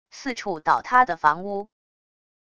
四处倒塌的房屋wav音频